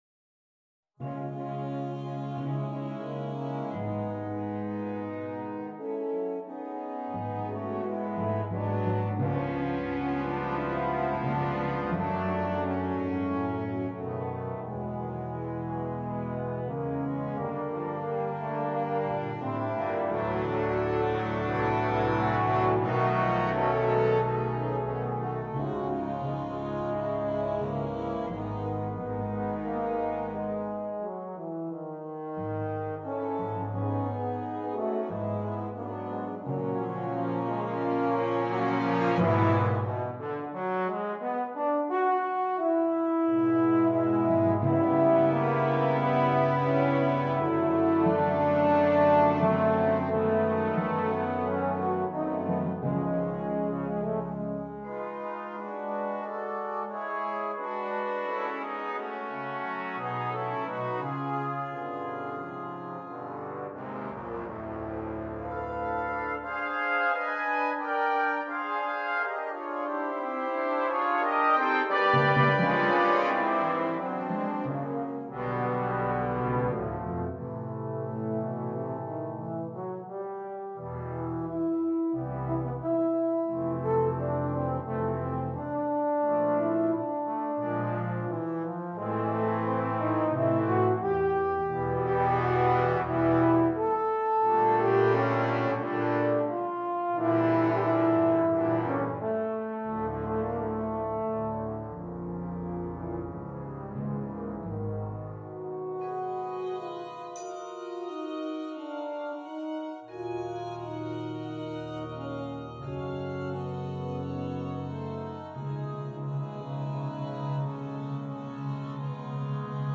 Besetzung: Euphonium Solo & Brass Band